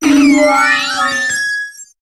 Cri de Togekiss dans Pokémon HOME.